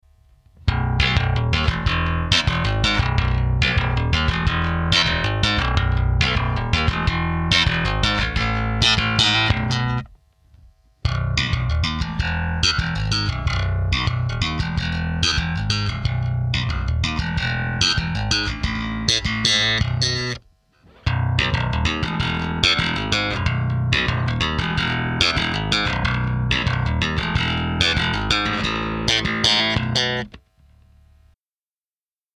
Cort GB35J - пятиструнный джазбас.
Звукосниматели не вынимал, но по идее и по звуку там керамические магниты, но несмотря на это и на агатис, звучит Cort GB35J очень прилично.
2. Слэп 830,92 Кб
slap.mp3